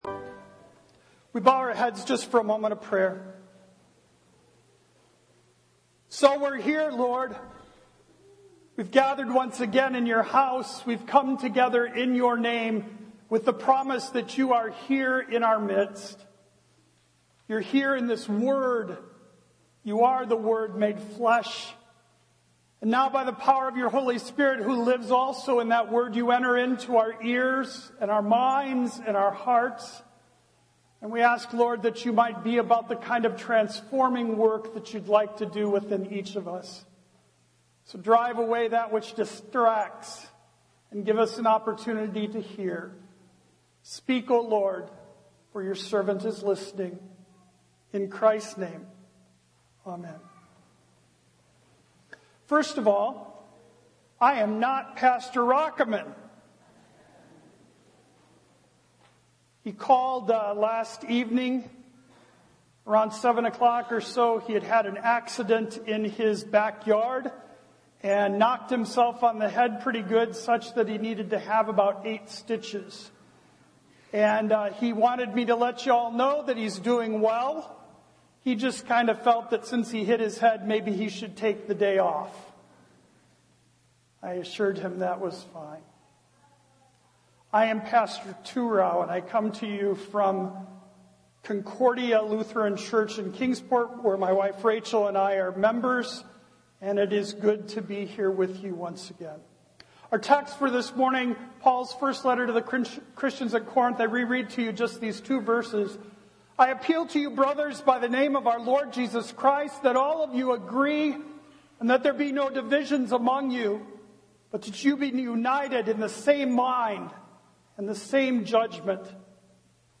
1 Corinthians 1:10-18 Audio Sermon